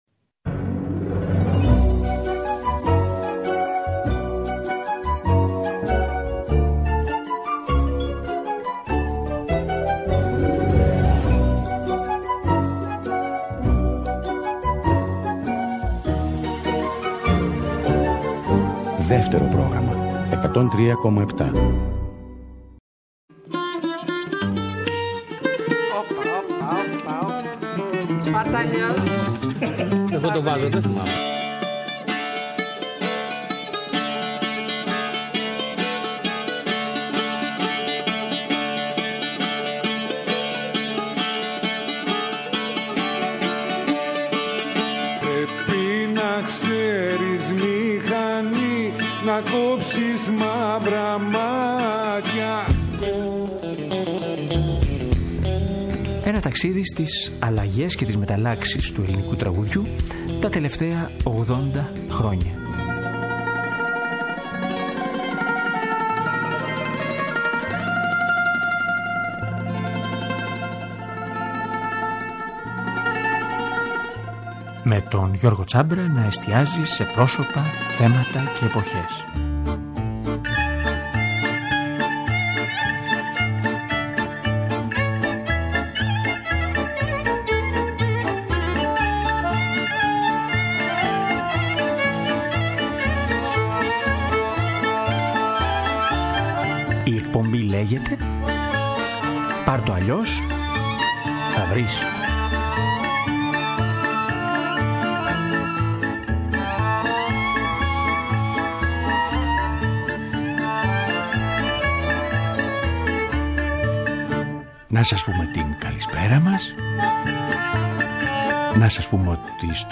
Μια συζήτηση, τα τραγούδια της «Απλής μετάβασης» και ένα ταξίδι σε τραγούδια από το ελληνικό μουσικό θέατρο ( Μέρος δεύτερο )